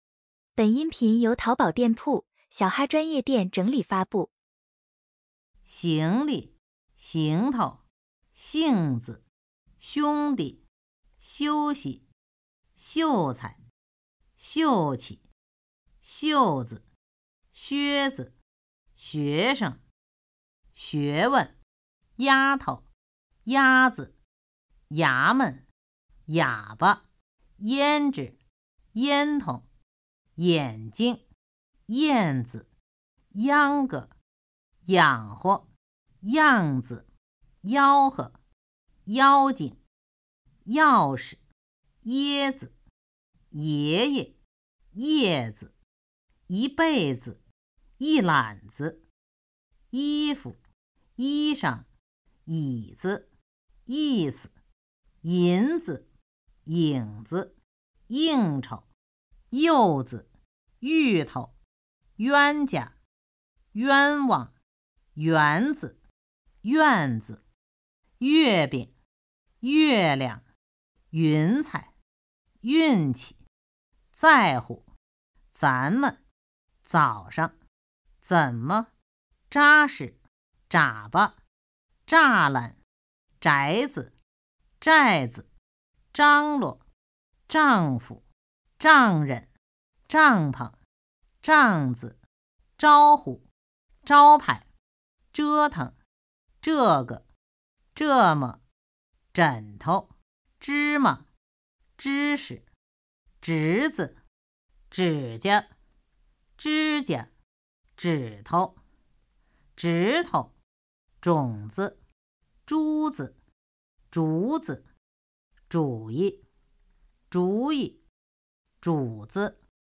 轻声501到594.mp3
普通话水平测试 > 普通话水平测试资料包 > 01-轻声词语表